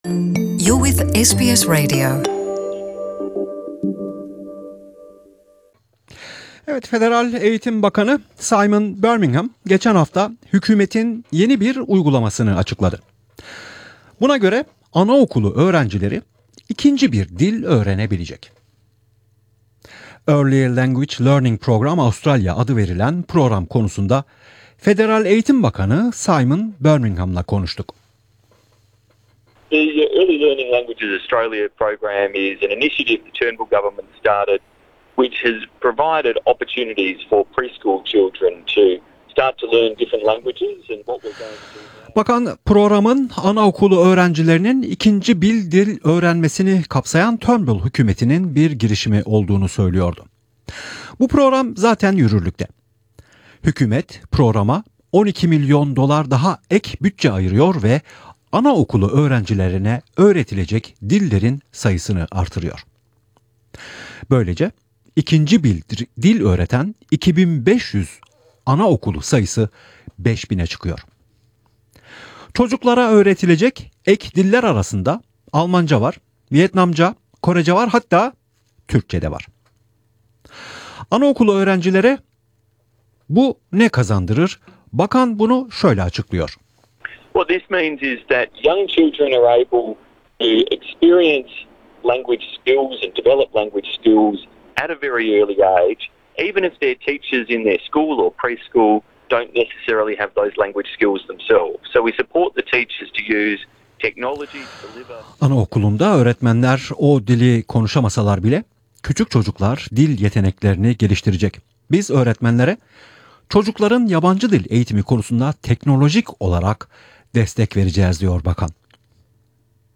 Federal Eğitim Bakanı Simon Birmingham ile ana okullarında Türkçe eğitimi konusunda söyleşi.